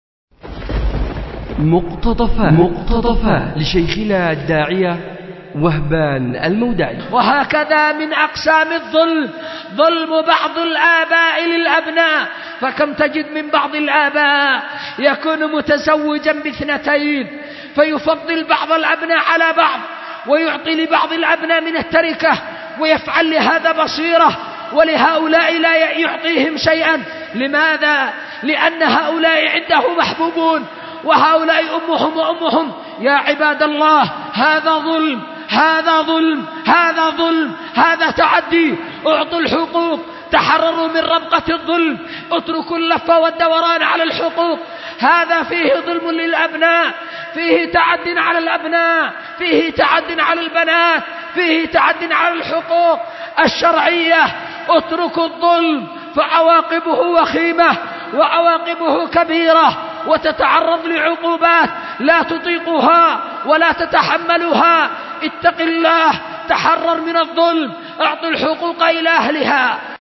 أُلقي بدار الحديث للعلوم الشرعية بمسجد ذي النورين ـ اليمن ـ ذمار